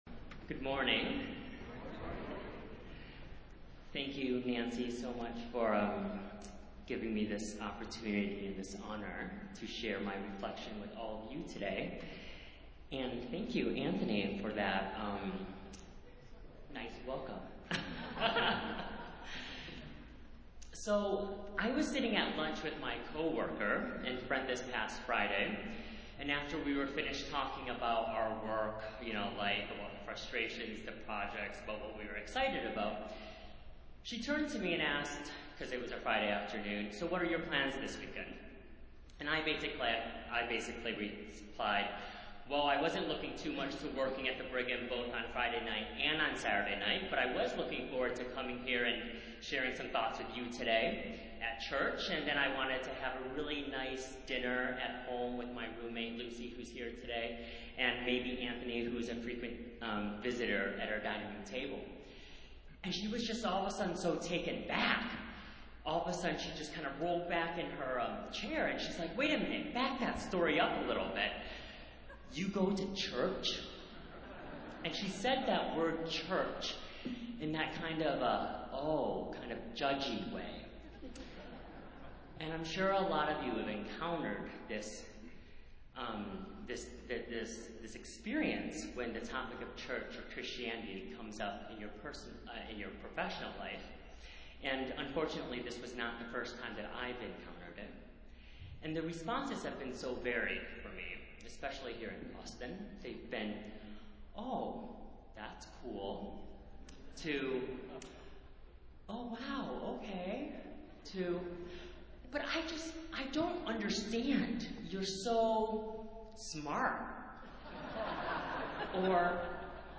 Festival Worship - Consecration Sunday